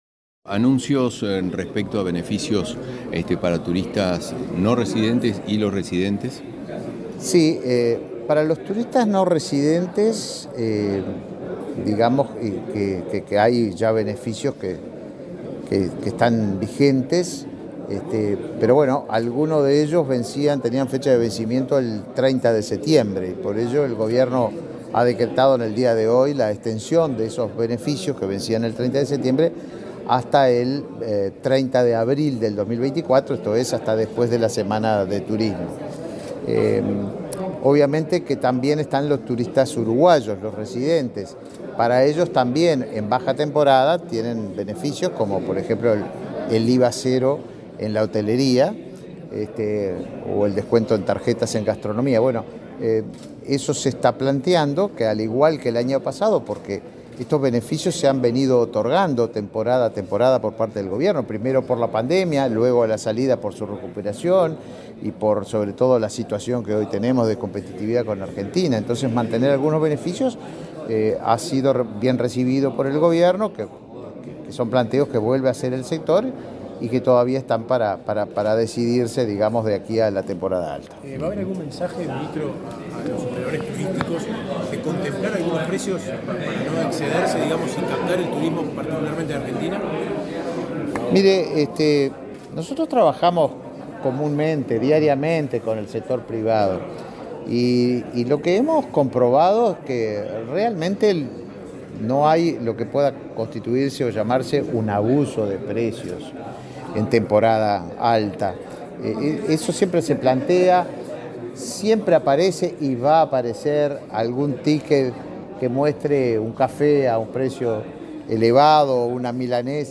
Declaraciones del ministro de Turismo, Tabaré Viera
Luego, dialogó con la prensa.